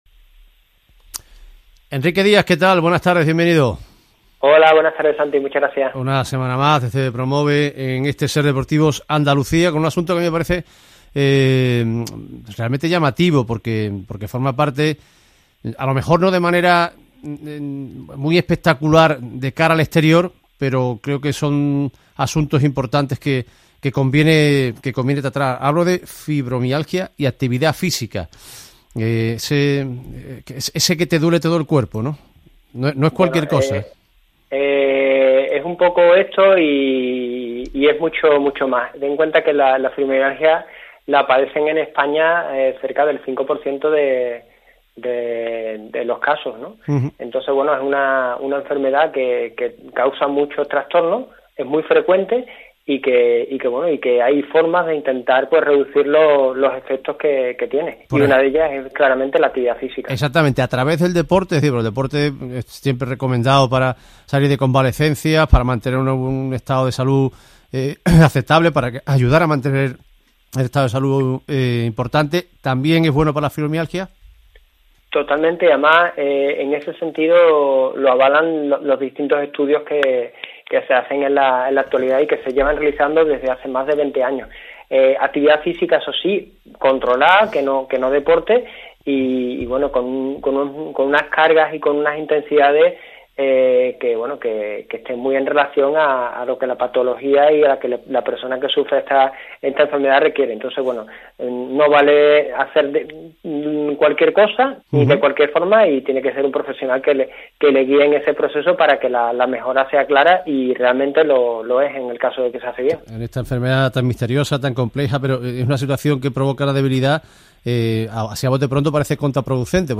Quizás después de escuchar esta entrevista cambies de opinión y con ayuda de un profesional consigas hacer algo y con ello mejorar tu salud.